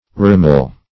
rameal.mp3